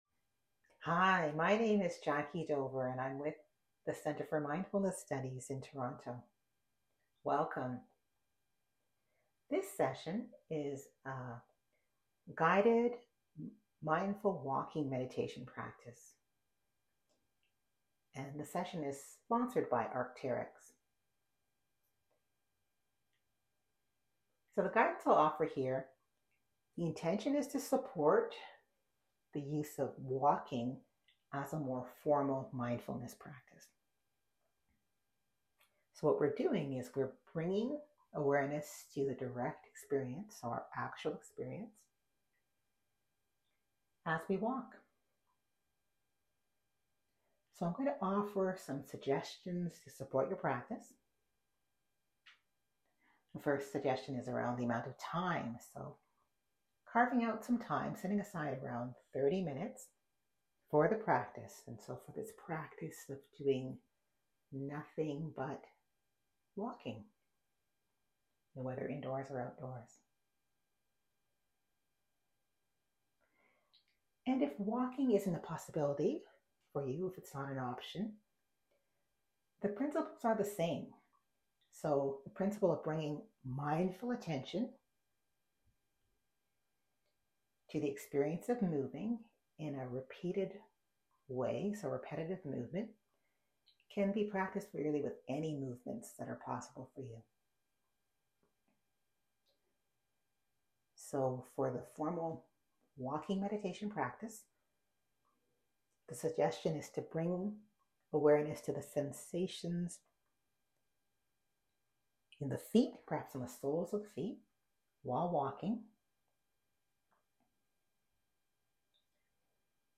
Mindfulness Challenge 2023: Guided Walking Meditations
Walking-Meditation.m4a